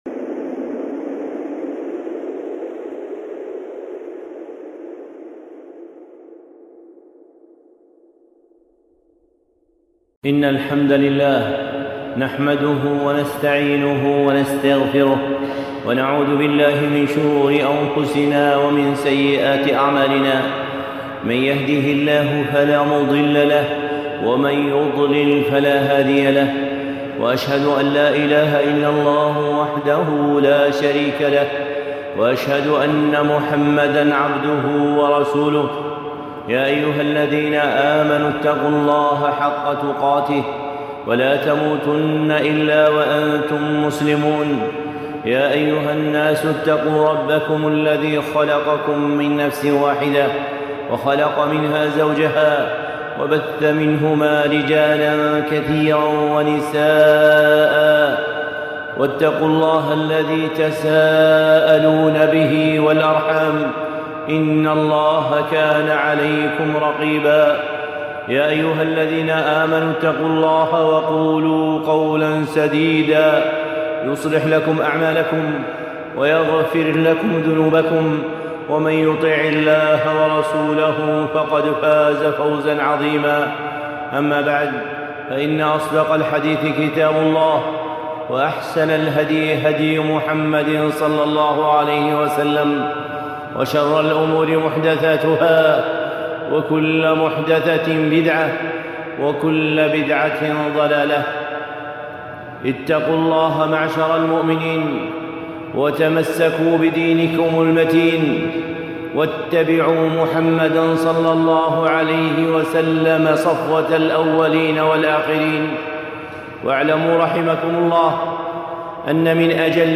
خطبة (تحقيق الاتباع